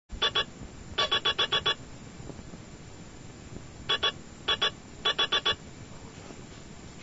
・IR受光素子と、ACカップリングコンデンサ、分圧抵抗によるアッテネータ、AFアンプIC、スピーカーで構成
赤外線モニター受信機の音 MP3 28kB